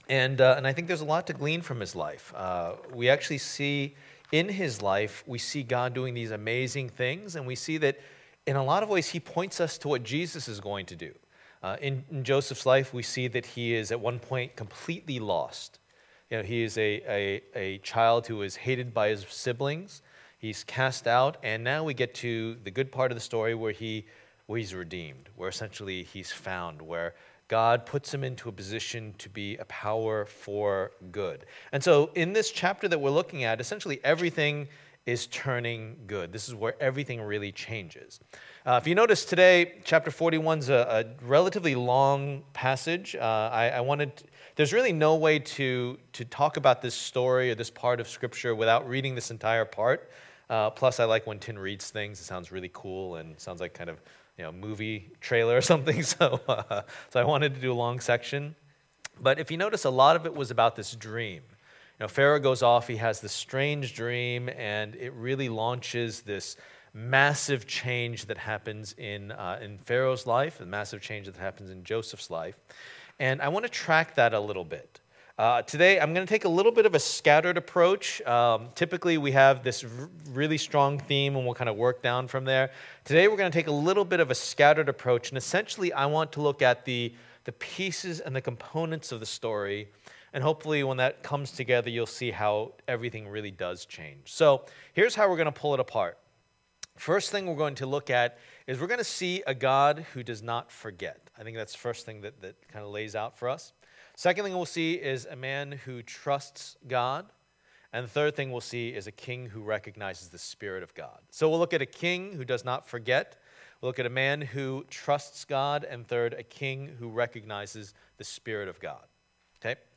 Passage: Genesis 41:1-36 Service Type: Lord's Day